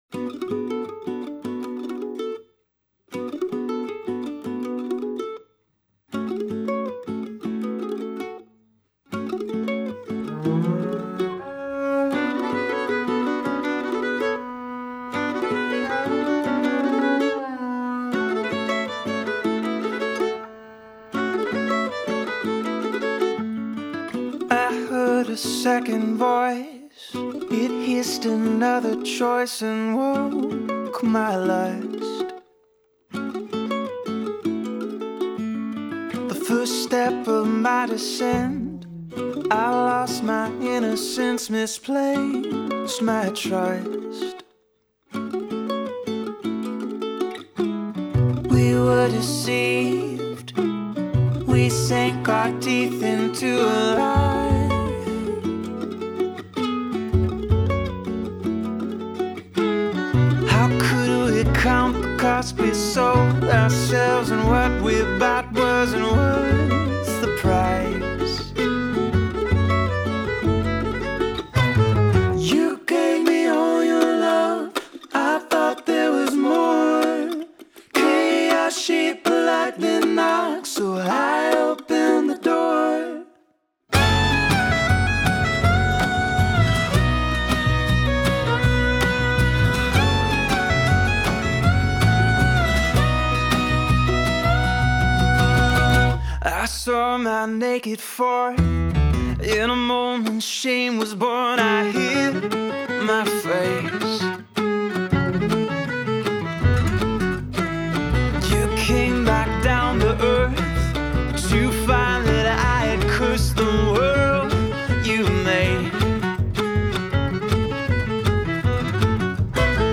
Bass Recordings
Americana
upright